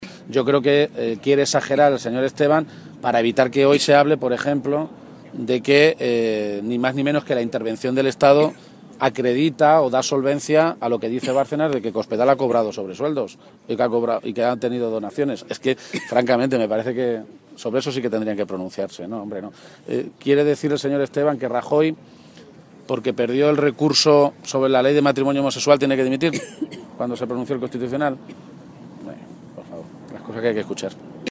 Emiliano García-Page, secretario General del PSOE de Castilla-La Mancha
García-Page se pronunciaba de esta manera esta mañana en Toledo, a preguntas de los medios de comunicación, e insistía en lo “curioso” de que se diera a conocer ayer una sentencia que aún las partes no tienen en su poder, “seguramente para tapar el desastroso dato de paro que dio ayer Castilla-La Mancha o los buenos resultados económicos” que él mismo ofreció ayer como alcalde de Toledo, con el mayor superávit municipal en la historia de la democracia.
Cortes de audio de la rueda de prensa